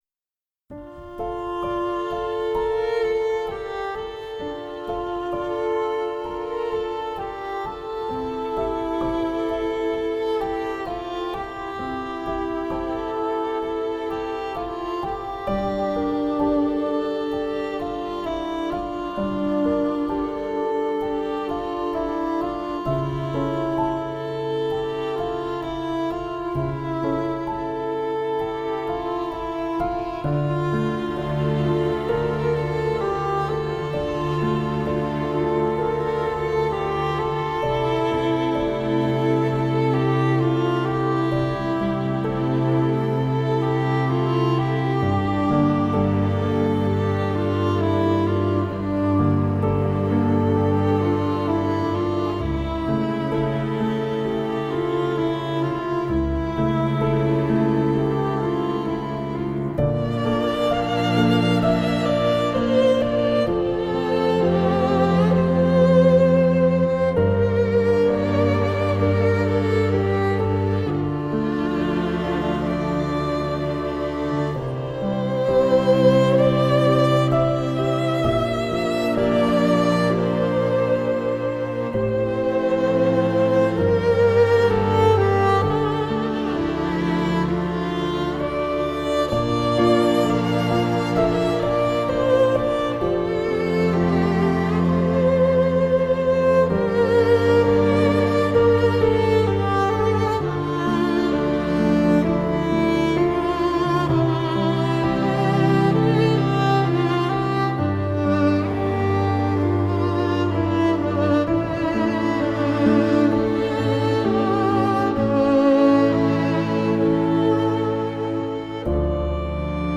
tema dizi müziği, duygusal hüzünlü rahatlatıcı fon müziği.